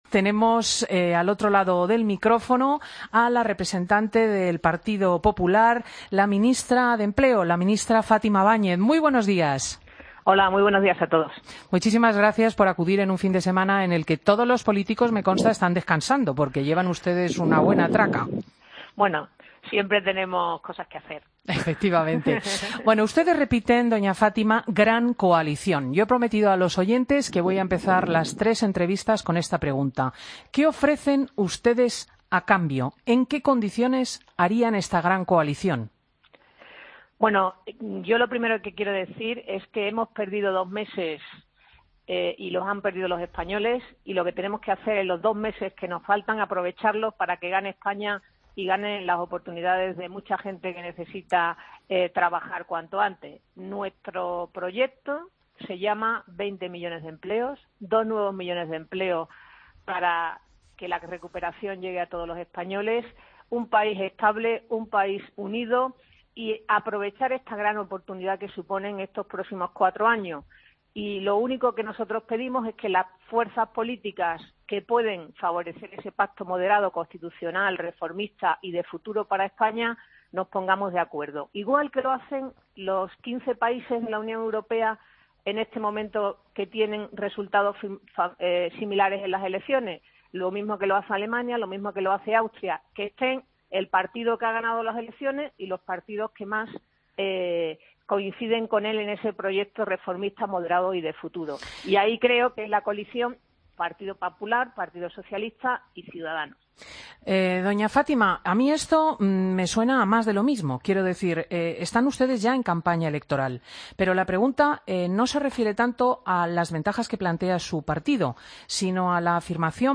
Escucha la entrevista a Fátima Báñez, Ministra de Empleo en funciones, en Fin de Semana COPE